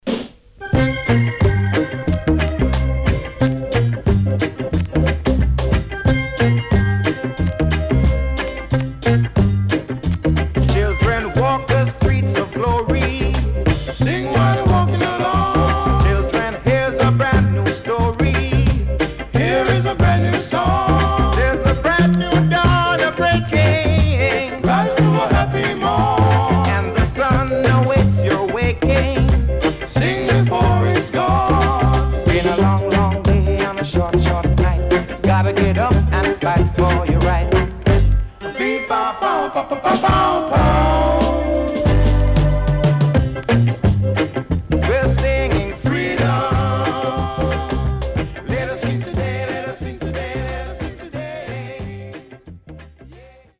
Rare.Nice Funky Reggae